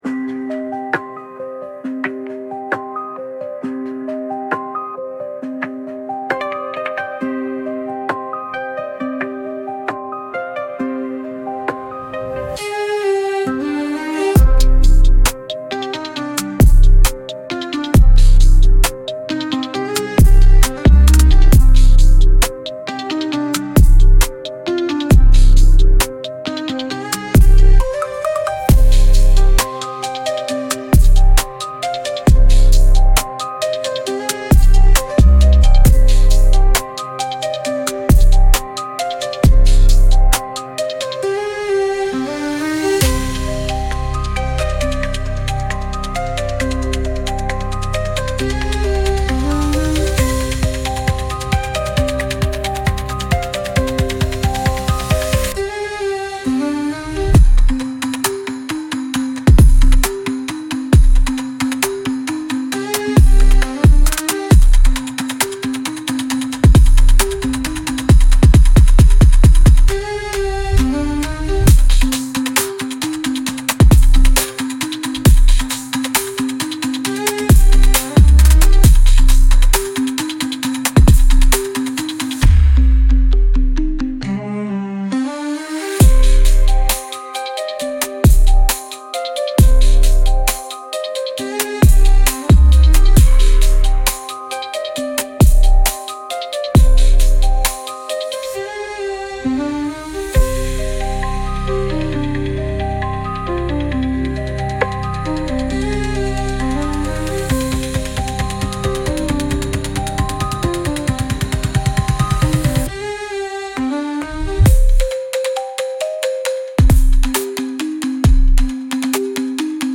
Instrumental - Tailspin Mirage